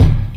Kick (Answer).wav